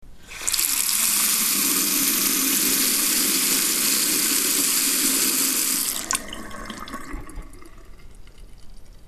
Play Washing Hands - SoundBoardGuy
Play, download and share washing hands original sound button!!!!
wasserhahn-hande-waschen.mp3